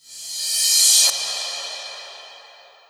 RevCrash MadFlavor.wav